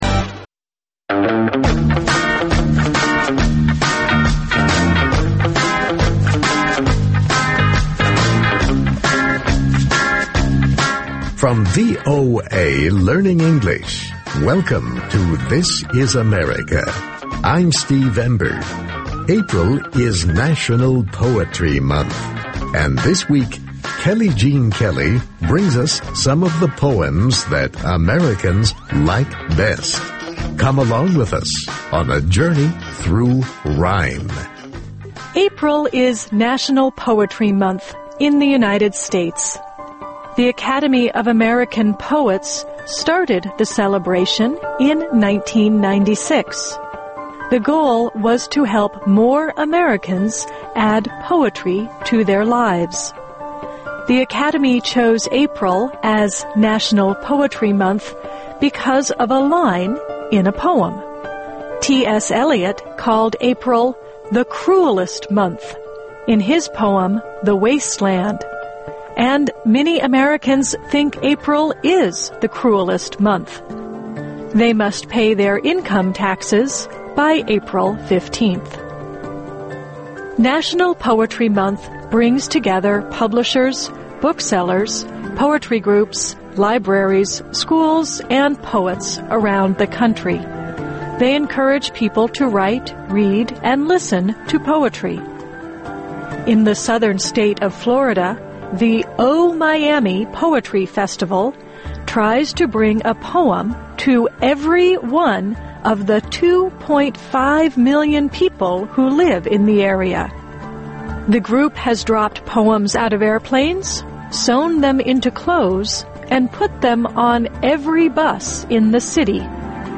ინგლისური ენის გაკვეთილები ("ამერიკის ხმის" გადაცემა)